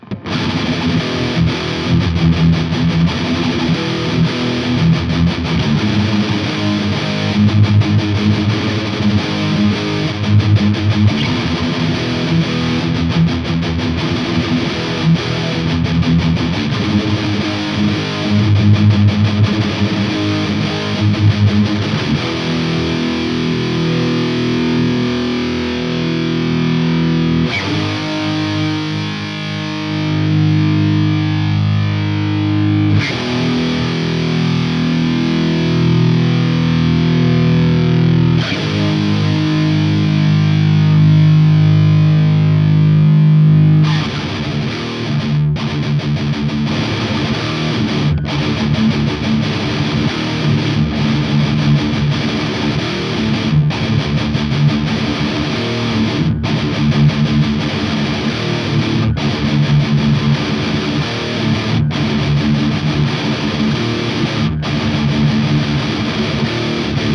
voilà de nouveau reamp où j'ai mis un son de base plus dans mes goûts.
101A : canal Orange - Orange to Modern
2 sons de guitares sont mélangés.
Ces sons sont bruts, j'ai juste nettoyé le trou au milieu.
Le son est bien plus précis qu'avec les 6L6.